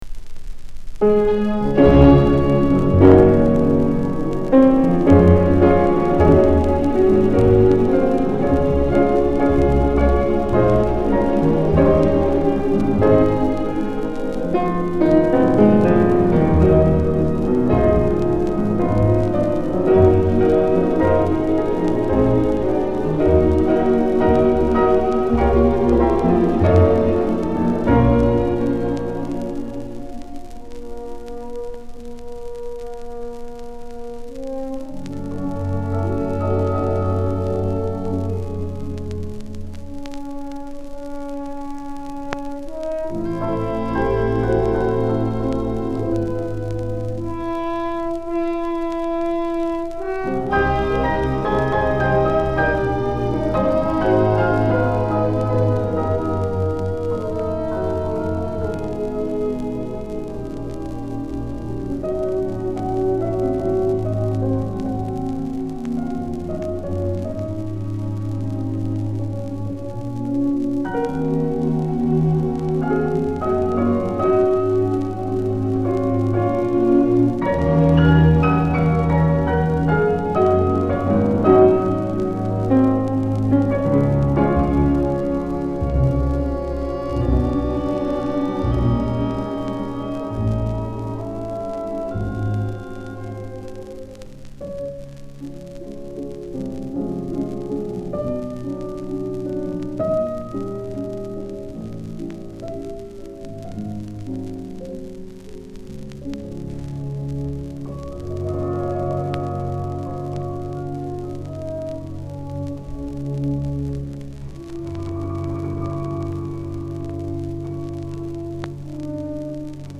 shellac 12"